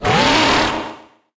Cri de Cerbyllin dans Pokémon HOME.